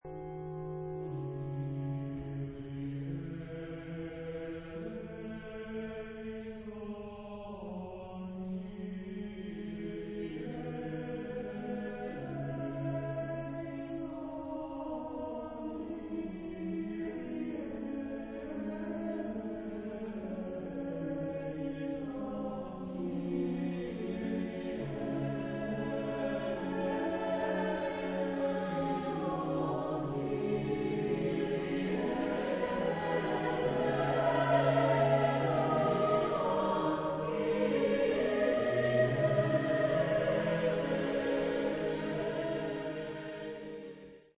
SATB (4 voix mixtes).
interprété par Wayne State University Concert Chorale